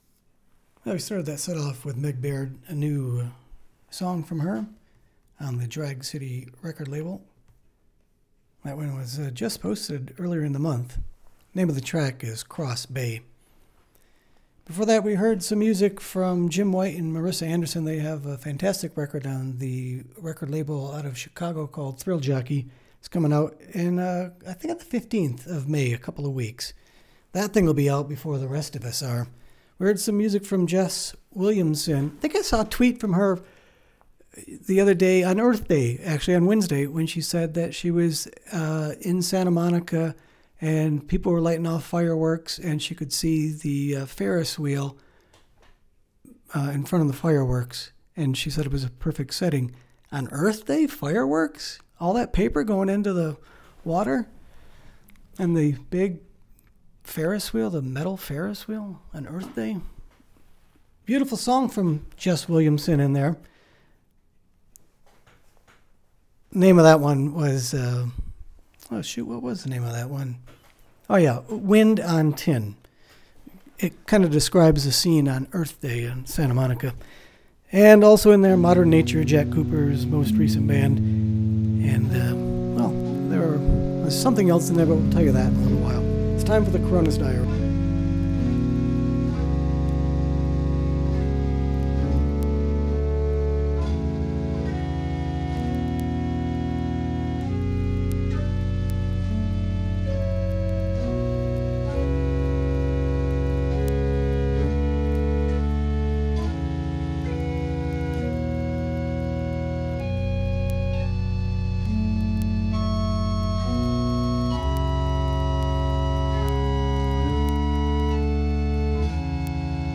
the radio station studio is closed during covid-19 restrictions. doing shows remotely. this edition starts with a bunch of new releases. new installments of the coronus diary in part two & part three